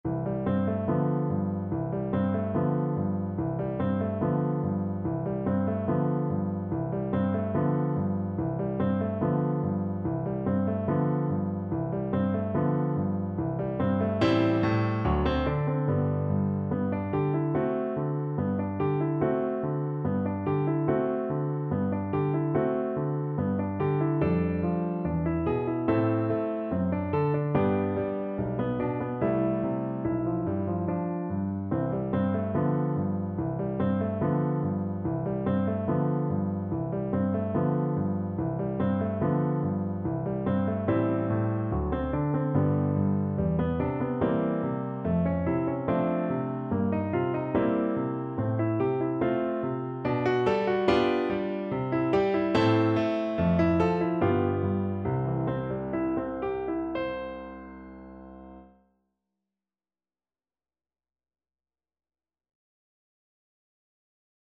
2/4 (View more 2/4 Music)
Allegretto moderato =72
Classical (View more Classical Viola Music)